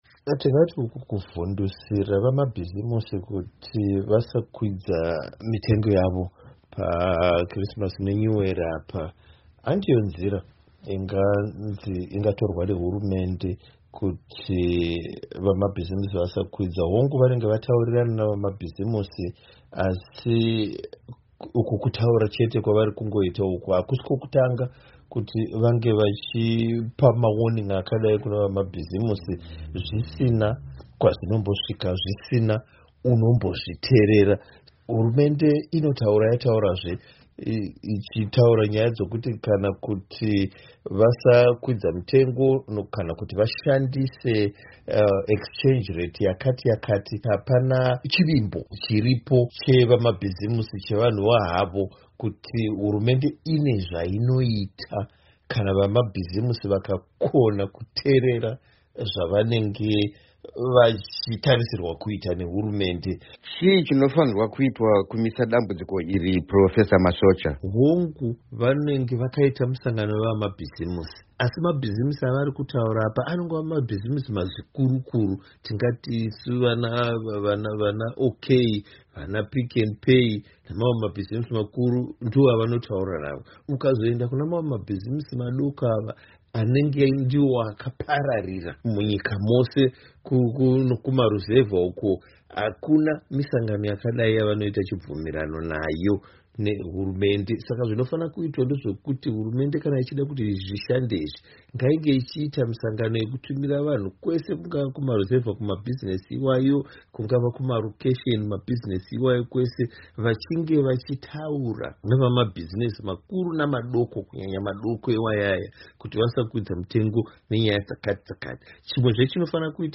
Hurukuron